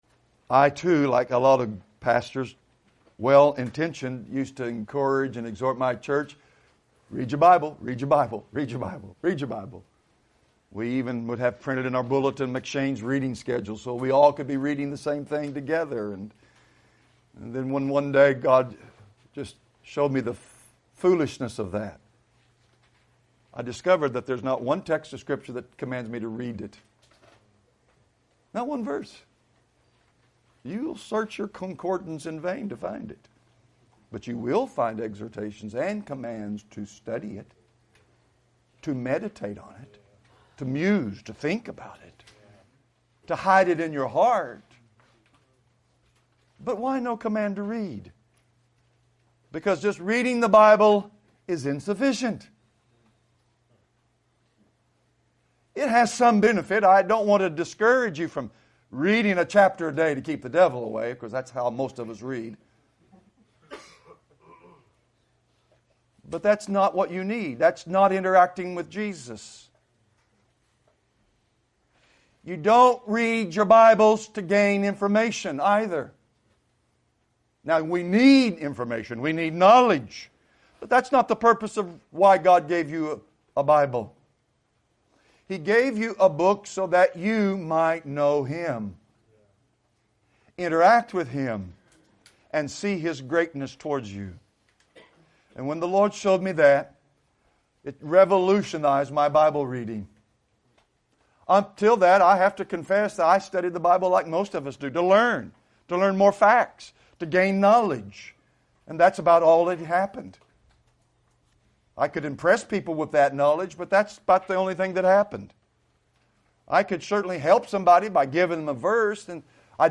2020 Category: Excerpts Topic